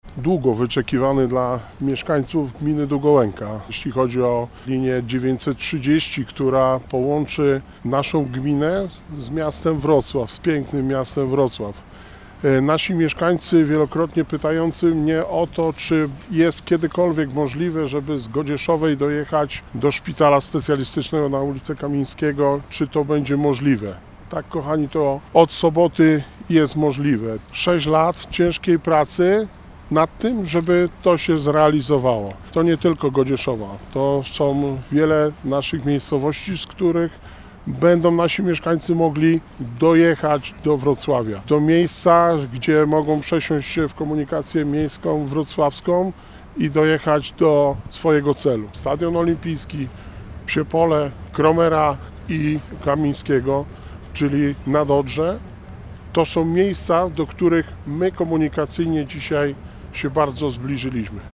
Spędziliśmy nad tym przedsięwzięciem wiele dni ciężkiej pracy – zaznacza Wojciech Błoński, wójt Gminy Długołęka.